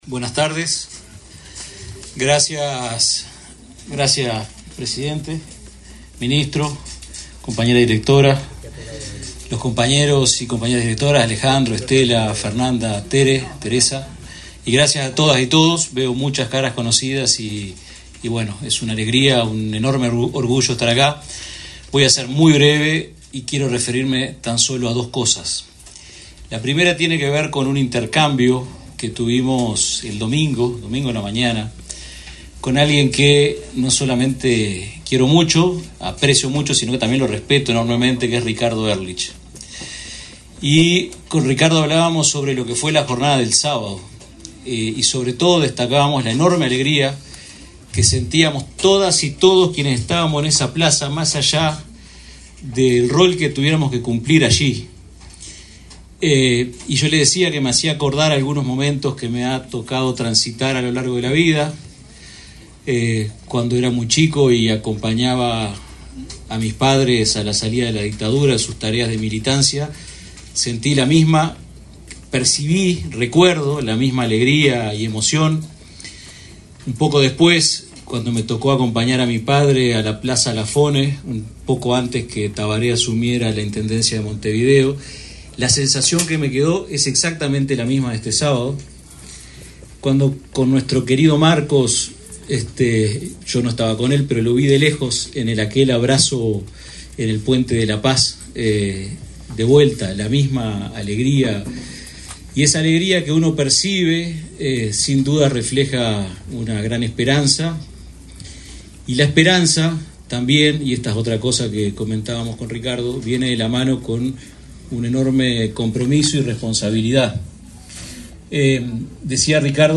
Palabras del subsecretario de Ambiente, Leonardo Herou
Palabras del subsecretario de Ambiente, Leonardo Herou 06/03/2025 Compartir Facebook X Copiar enlace WhatsApp LinkedIn El presidente de la República, profesor Yamandú Orsi, y la vicepresidenta, Carolina Cosse, participaron, este 6 de marzo, en la asunción de las autoridades del Ministerio de Ambiente. Edgardo Ortuño asumió como ministro, y Leonardo Herou como subsecretario.